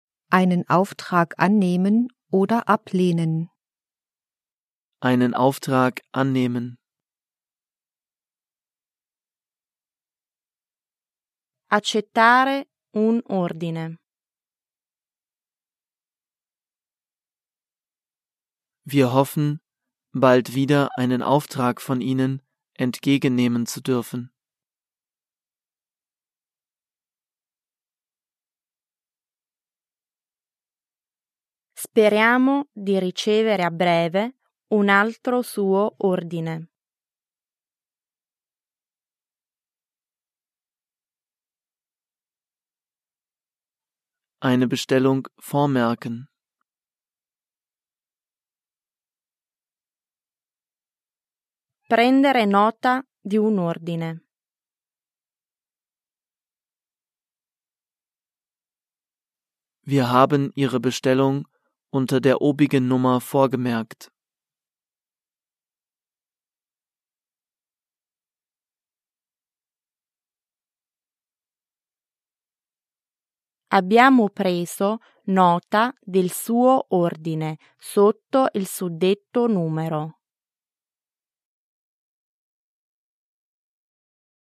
Übersetzungs- und Nachsprechpausen sorgen für die Selbstkontrolle.